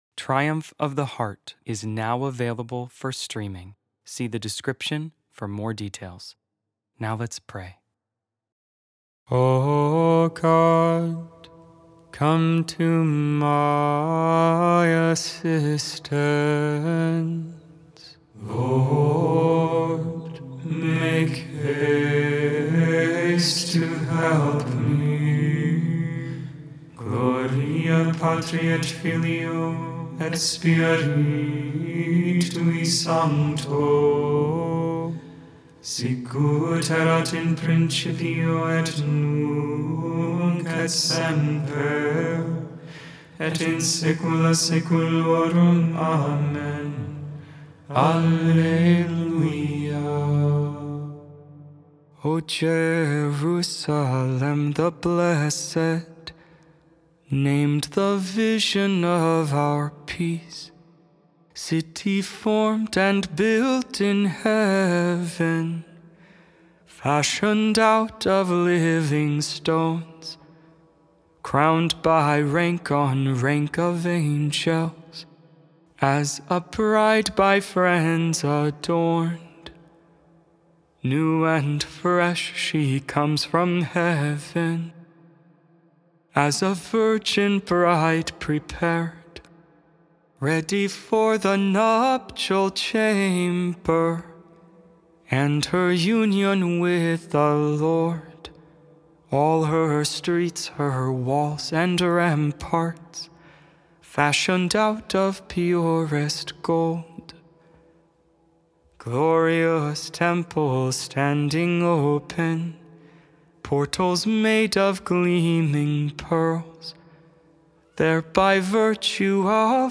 Feast of the Dedication of St. John Lateran Basilica Made without AI. 100% human vocals, 100% real prayer.